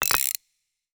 Rocket_upgrade (1).wav